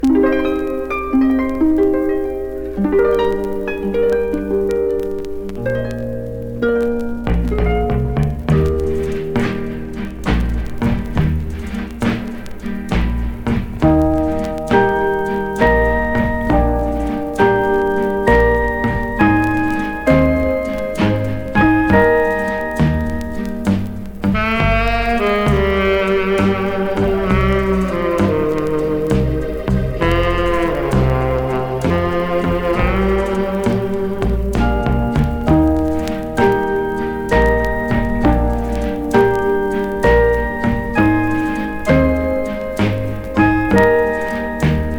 ハープの音ということもありますが、楽曲の中で響くきらめく演奏がたまりません。
Jazz, Pop, Easy Listening　USA　12inchレコード　33rpm　Stereo